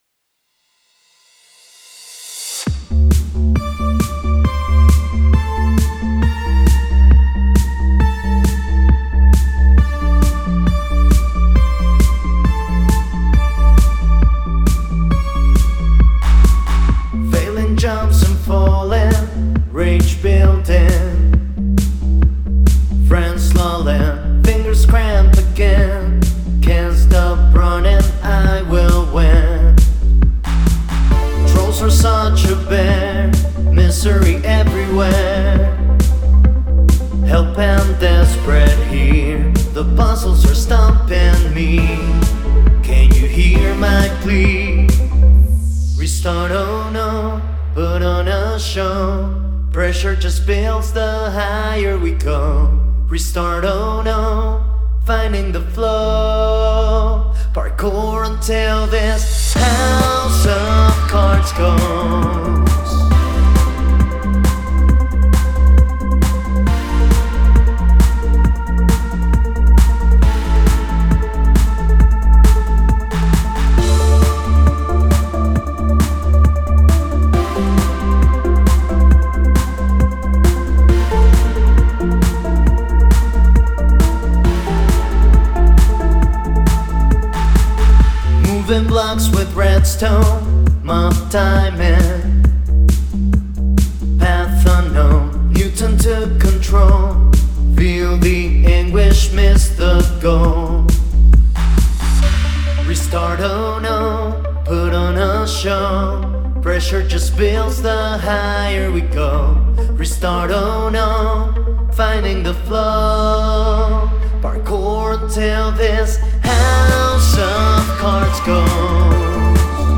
For the music I wanted some sort of electronic dance track.